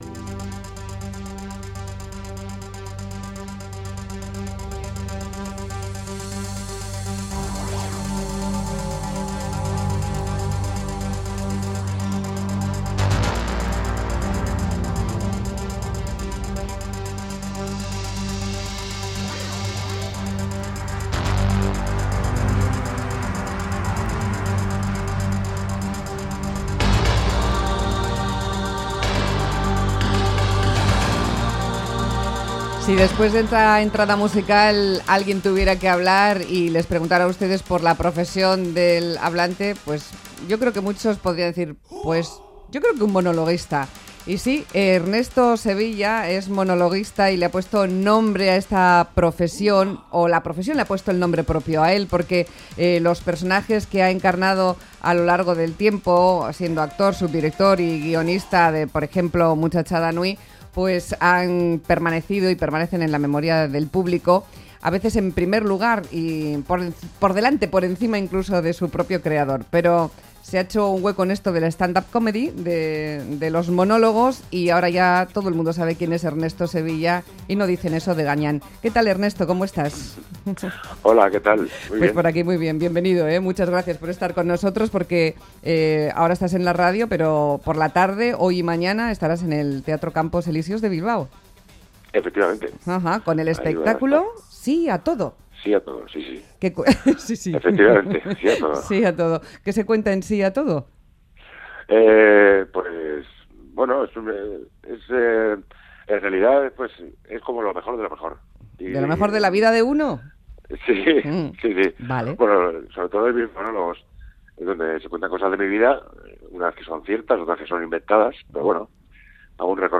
Radio Euskadi MÁS QUE PALABRAS Ernesto Sevilla siempre dice sí Última actualización: 09/05/2015 13:08 (UTC+2) “Sí a todo”: ése es el título del espectáculo de monólogos de Enrique Sevilla, genio del absurdo, mítico del clan de Muchachada Nui y Museo Coconut. Aprovechando que está en Bilbao con su espectáculo, nos apetece charlar con él.